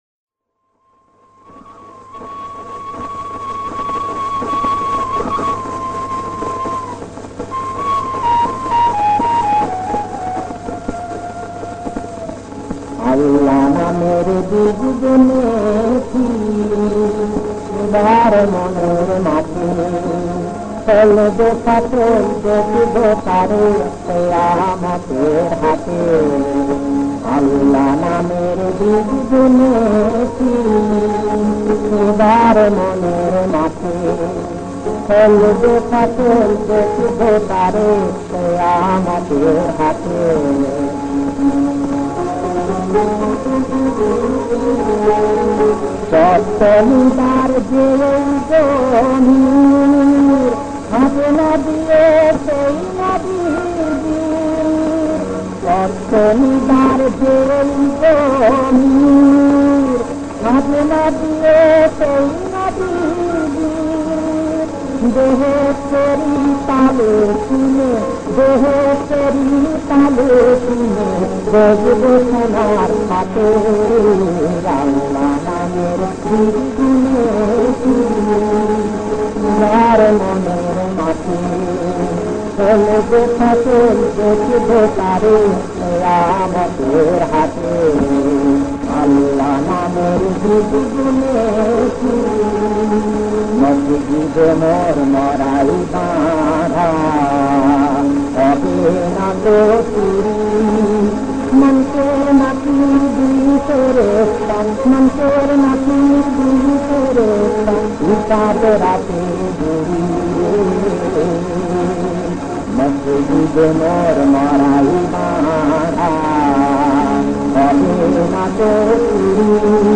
• বিষবাঙ্গ: ধর্মসঙ্গীত। ইসলামী গান। হামদ।
• সুরাঙ্গ: ভাটিয়ালি